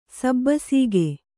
♪ sabbasīge